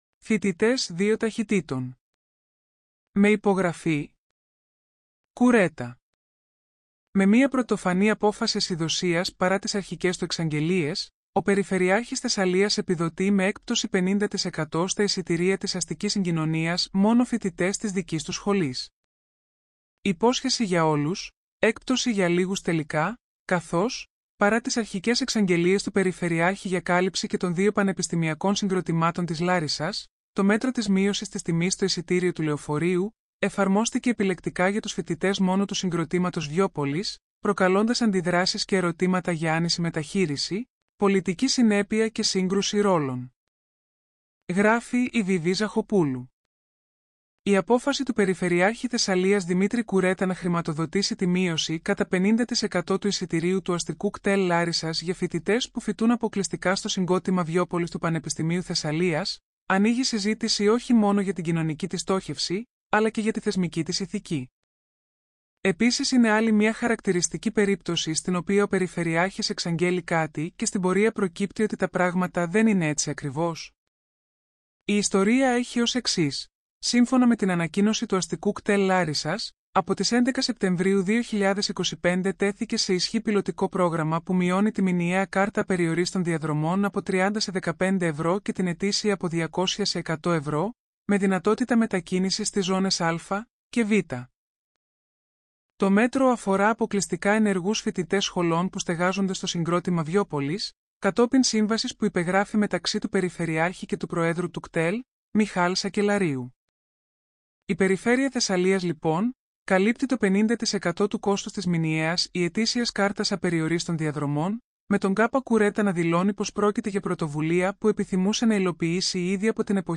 AI υποστηριζόμενο ηχητικό περιεχόμενο